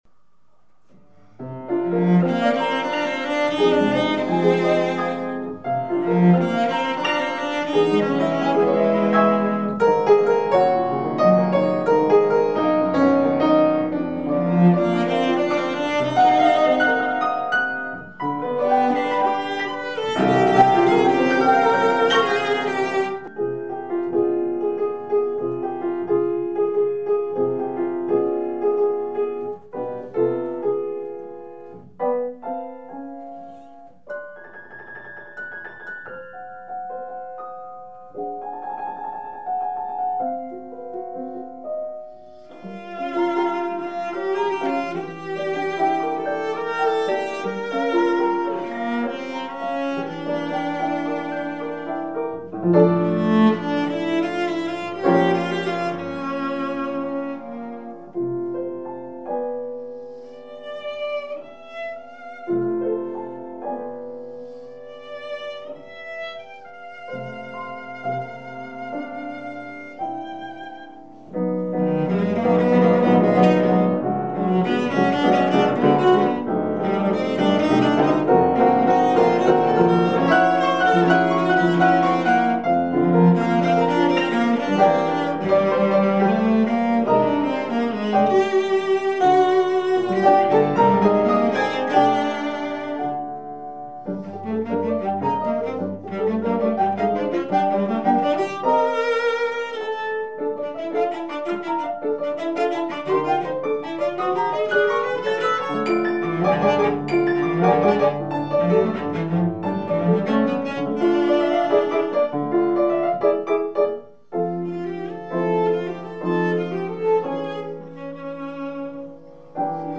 Sonate für Violoncello und Klavier op. 13
Mit Schwung, nicht zu schnell